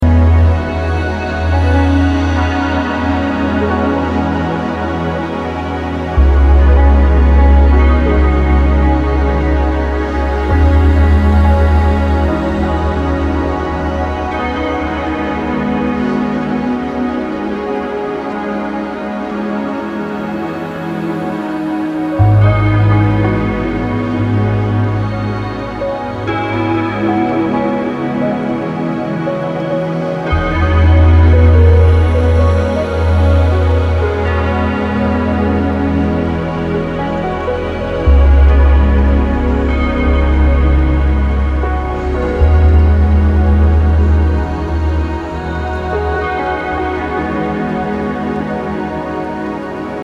Ambient, Drone >
Post Classical >